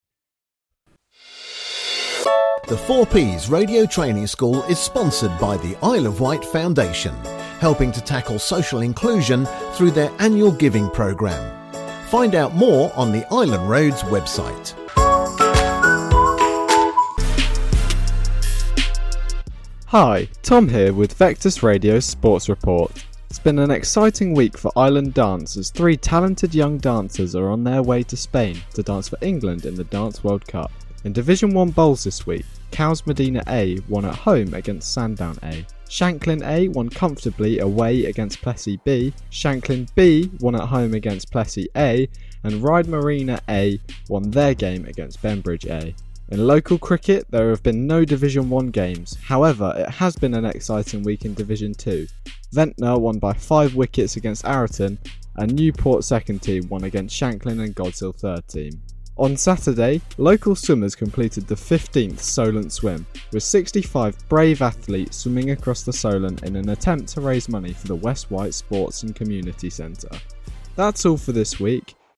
sports report